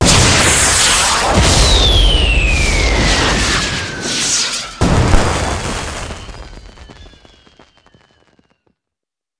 firework